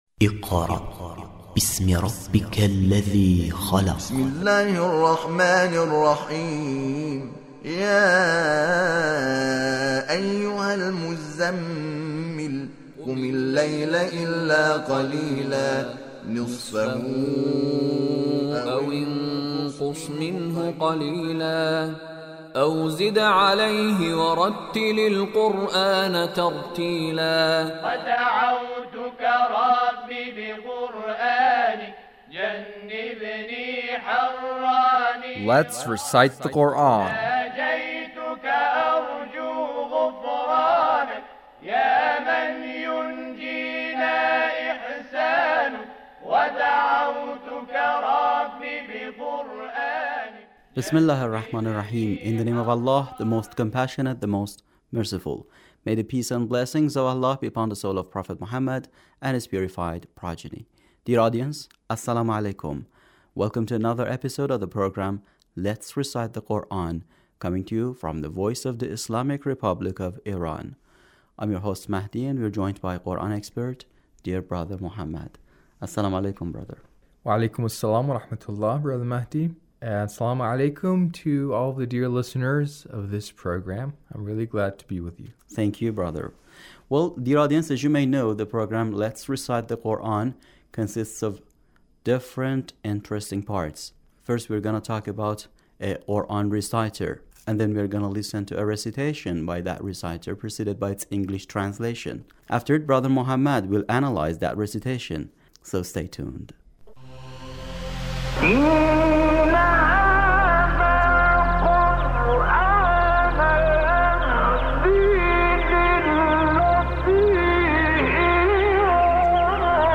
Mahmud Ali El-Benna recitation